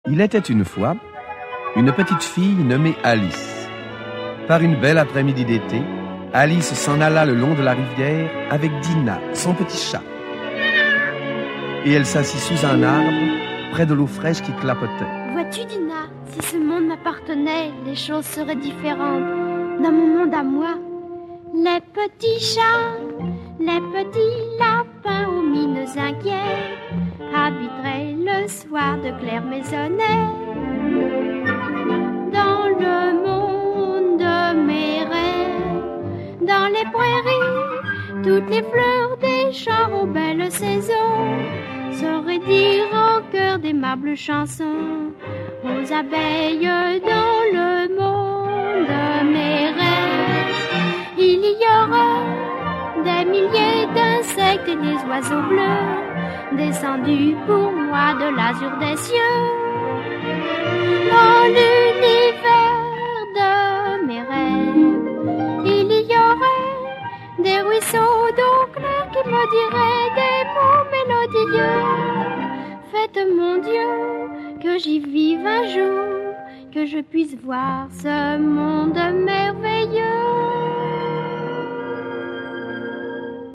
Les voix du rêve
Le récitant
Orchestre et choeurs
Enregistrement original de 1951 (extraits)